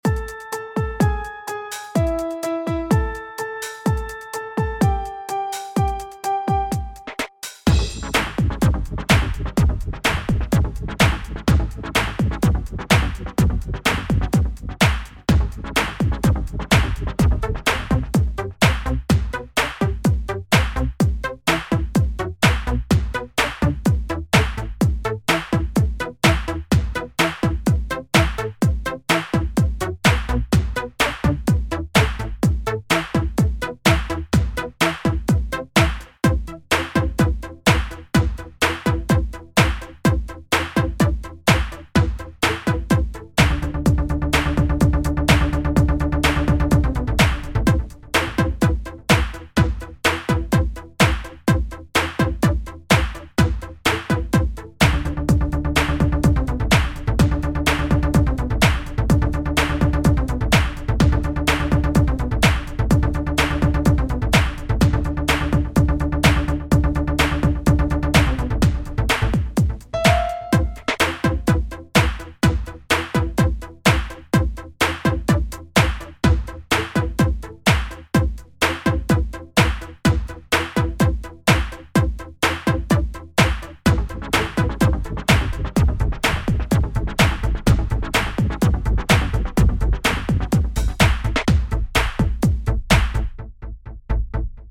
Dynamique House